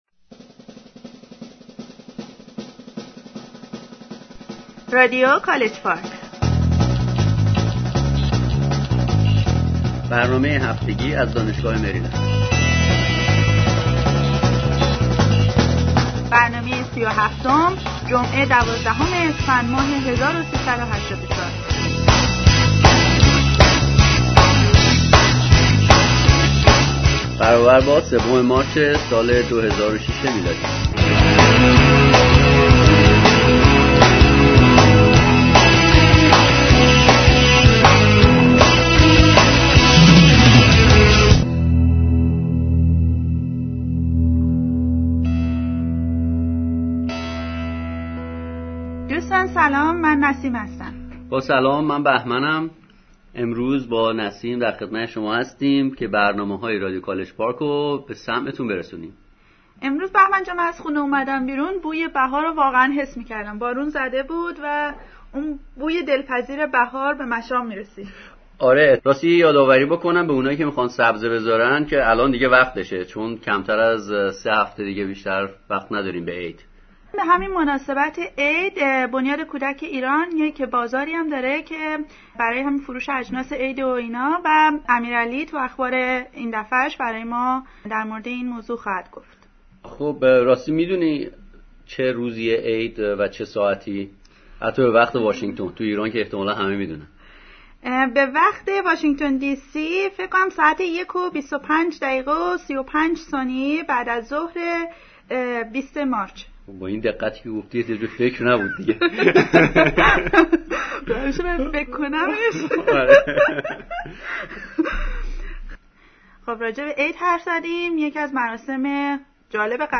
A report about the fire in the library of the law school of Tehran University